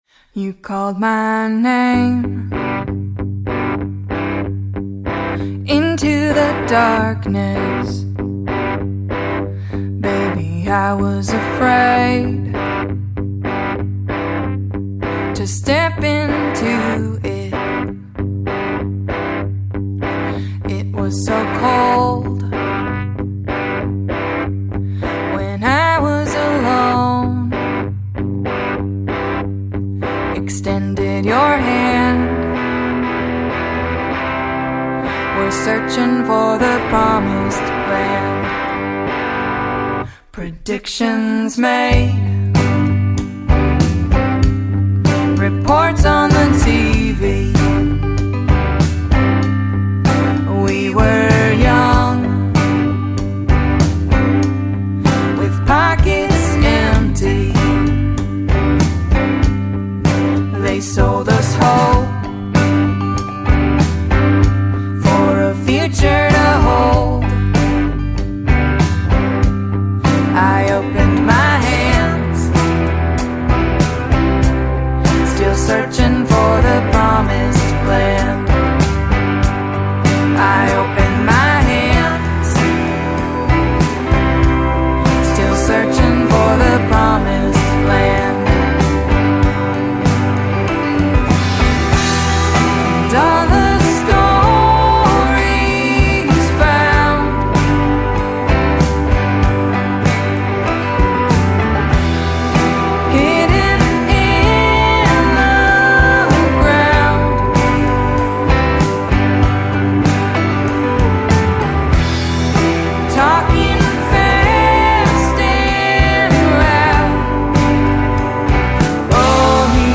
Americana